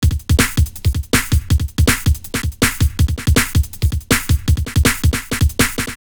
3_DrumLoops_1.wav